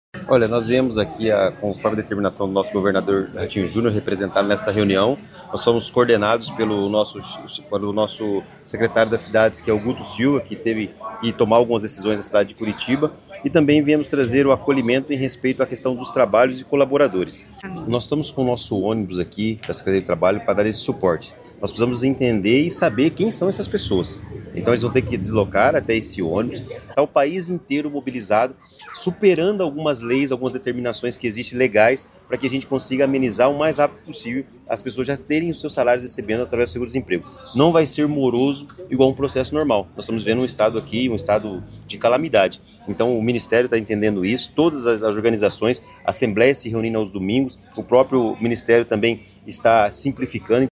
Sonora do secretário do Trabalho, Qualificação e Renda do Paraná, Do Carmo, sobre o auxílio a empresários e trabalhadores de Rio Bonito do Iguaçu